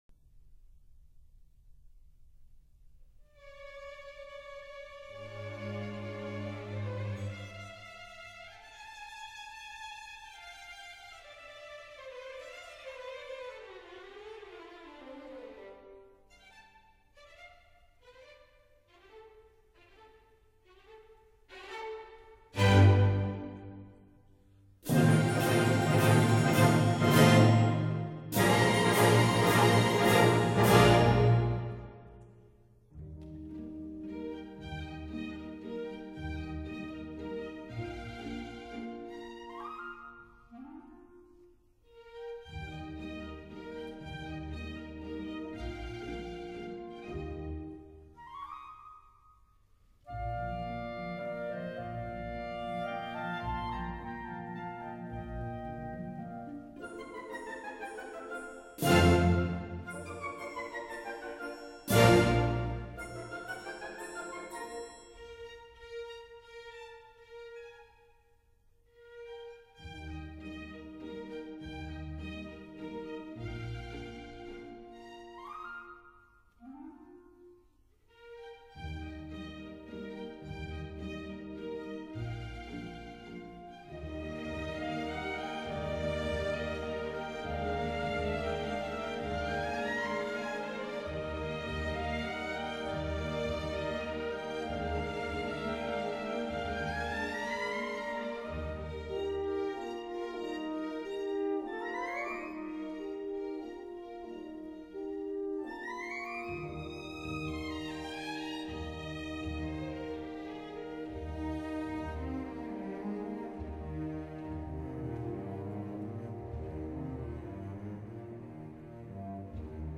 Andante
行板<02:09>